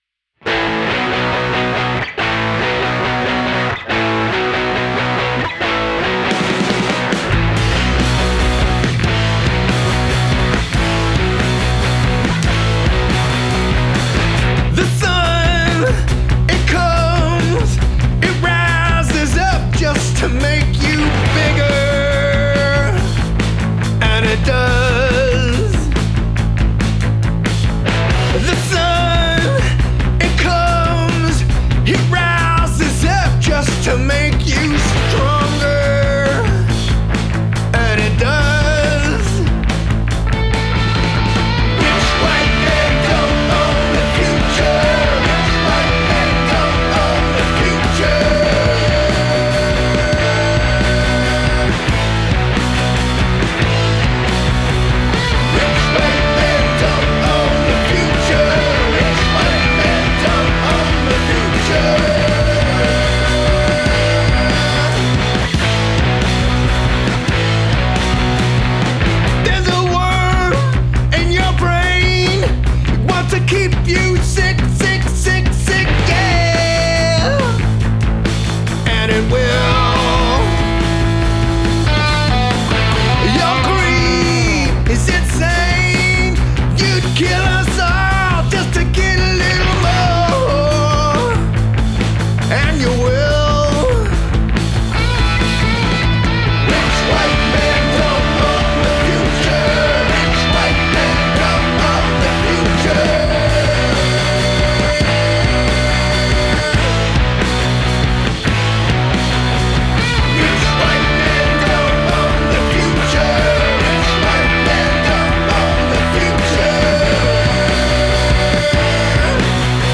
It’s punk rock. It’s loud.